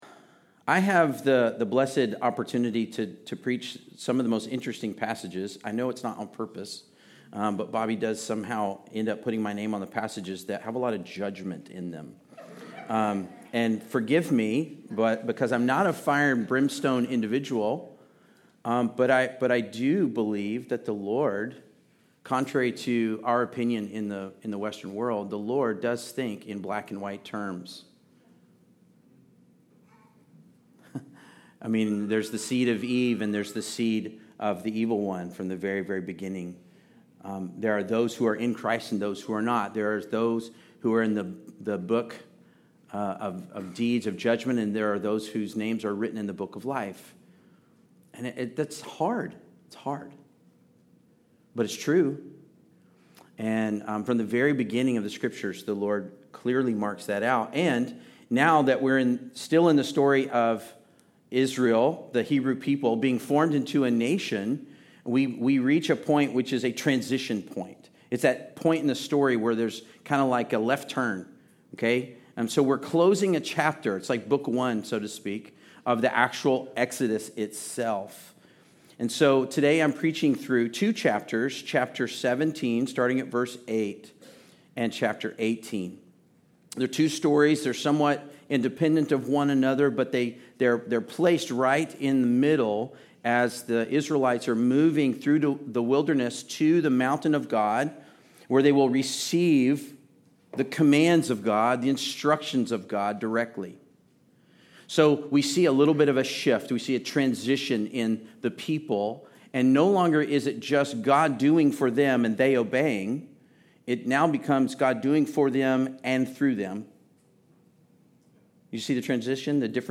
Passage: Exodus 17:8-18:27 Service Type: Sunday Service